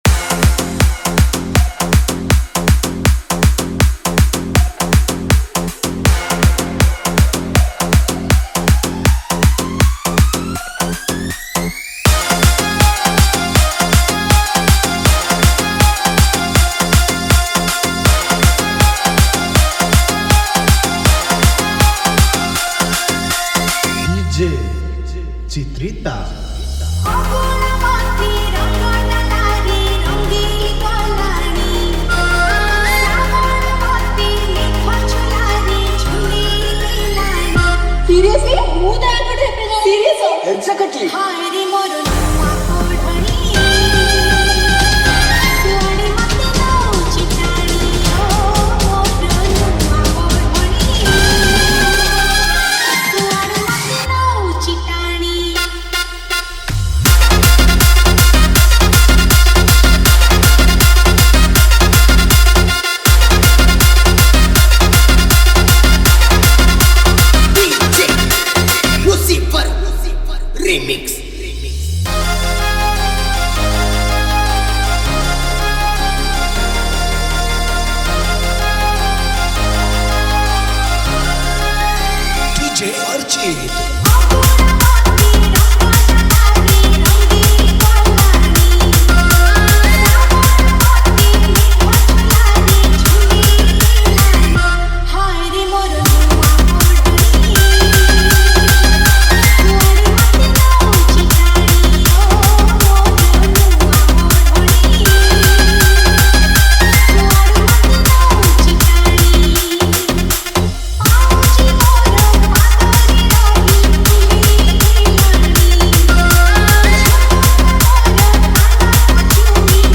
Category:  Odia Old Dj Song